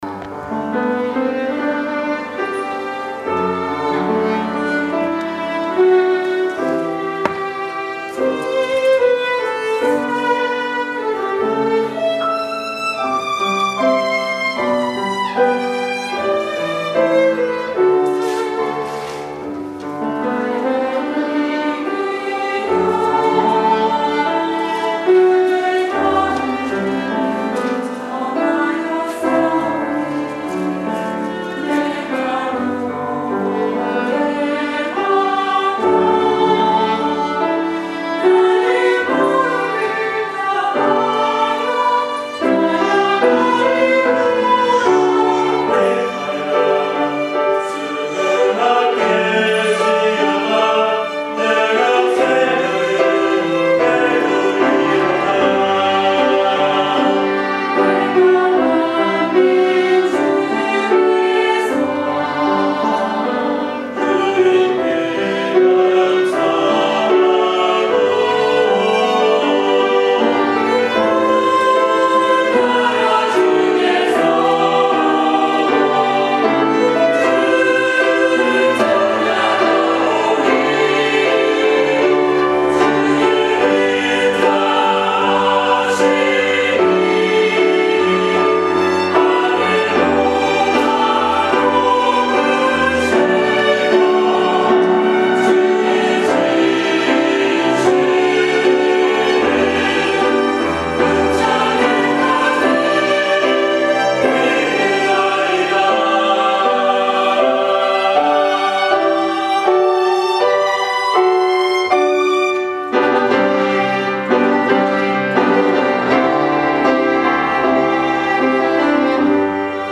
2월1일 찬양